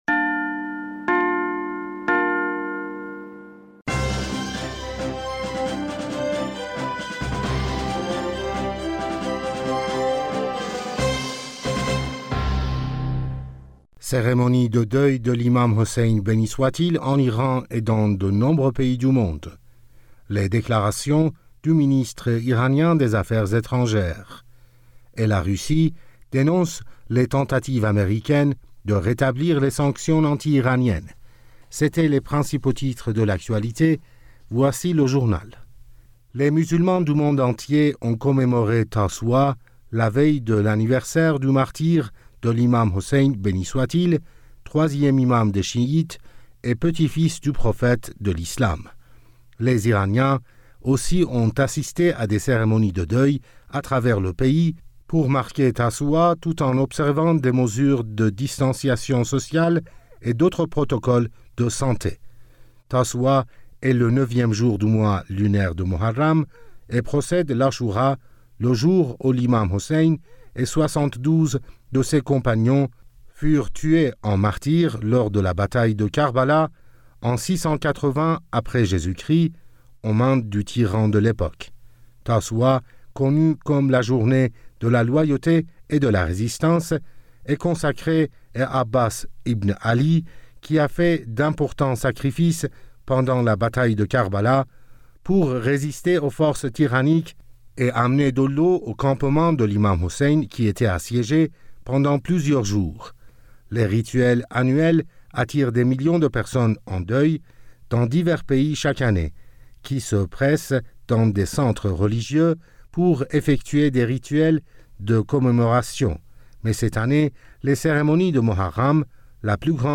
Bulletin d'information du 29 Aout 2020